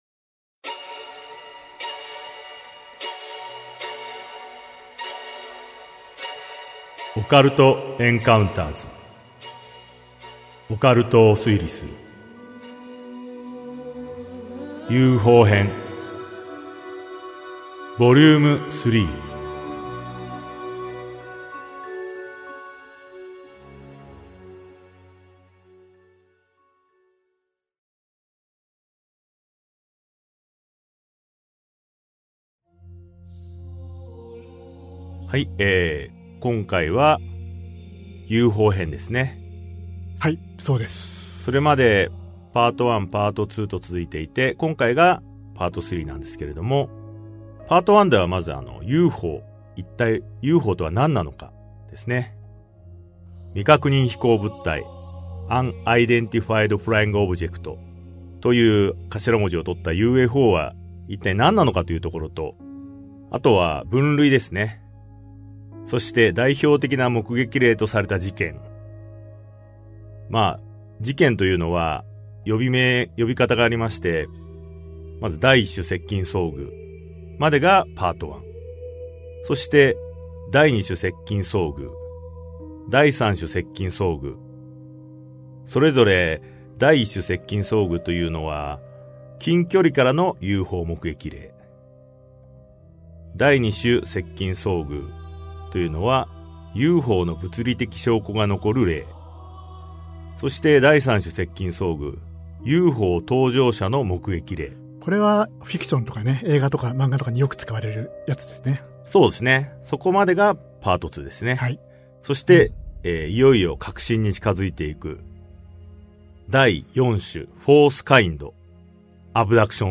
[オーディオブック] オカルト・エンカウンターズ オカルトを推理する Vol.03 UFO編3